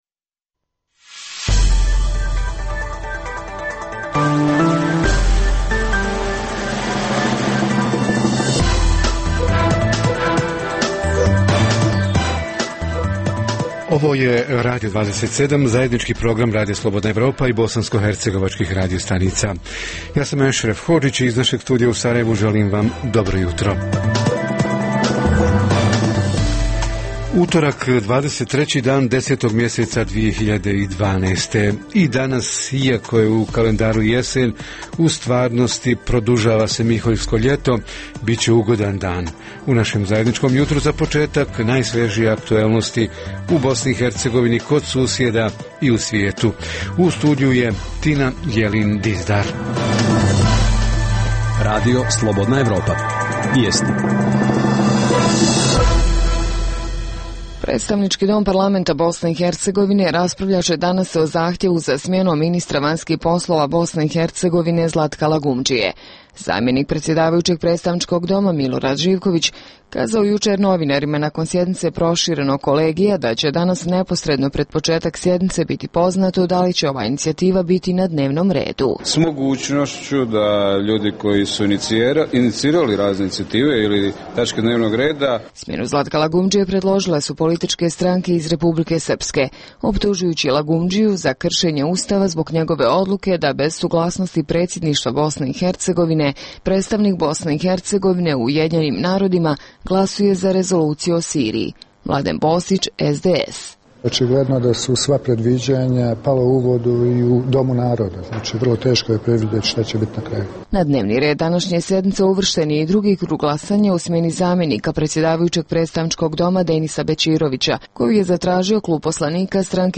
Javljanja uživo iz Mostara: šta donosi rebalans gradskog budžeta o kojem će se danas izjašnjavati Gradsko vijeće i šta će biti sa zakonodavnom vlašću u Mostaru za 10-ak dana, kada ističe mandat sadašnjim vijećnicima?